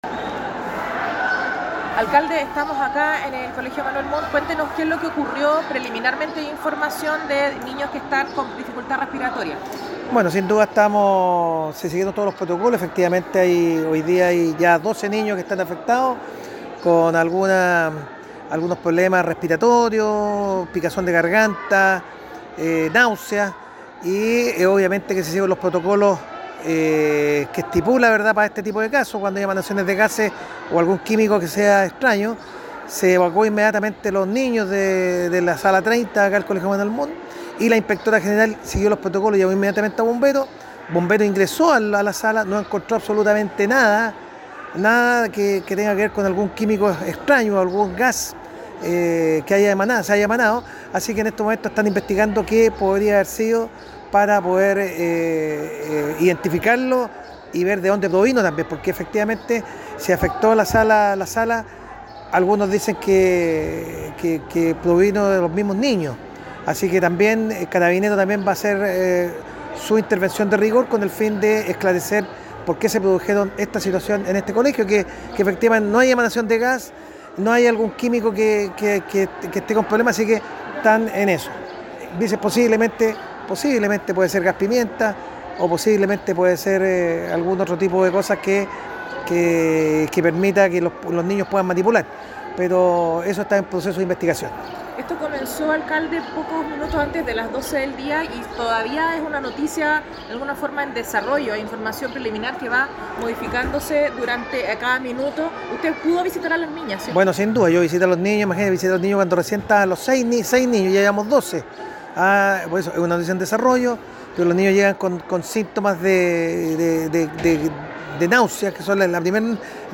alcalde-nelson-estay-por-gas-en-colegio-online-audio-converter.com_.mp3